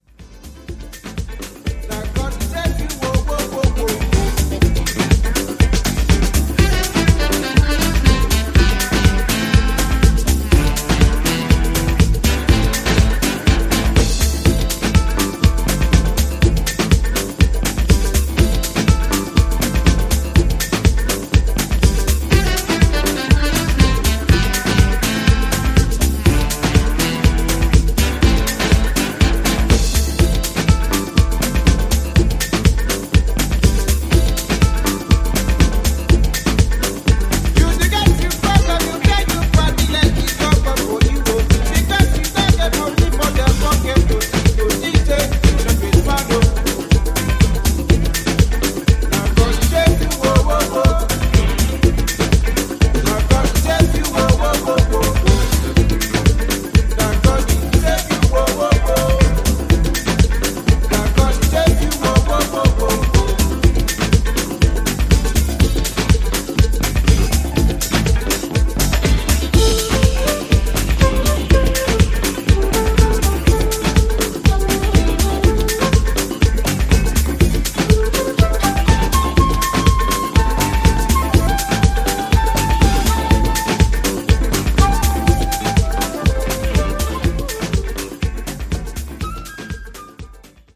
アフリカン・ブギー、ハイライフ、ズーク路線の楽曲をDJユースに捌いたディスコハウスを全4曲を披露しています！